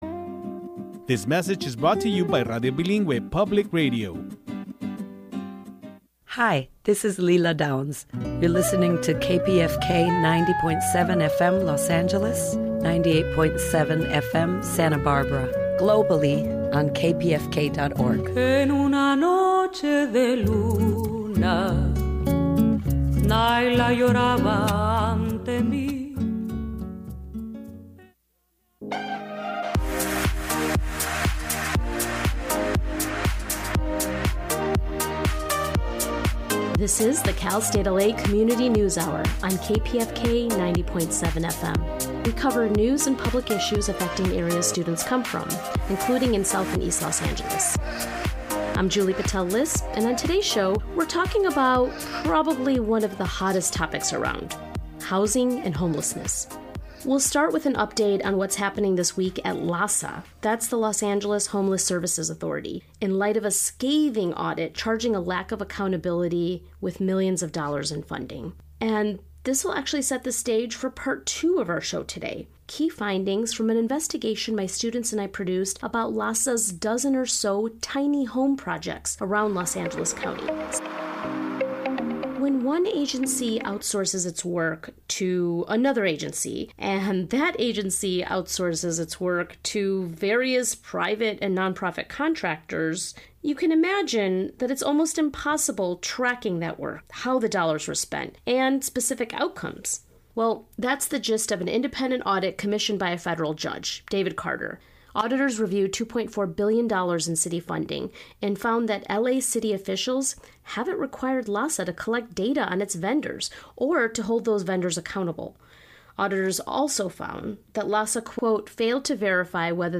Beneath the Surface features interviews with leading thinkers and activists on the important issues of the day, with a focus on deeper analysis.